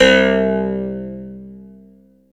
13 CLAV C2-R.wav